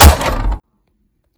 RepeatingBow_Fire.wav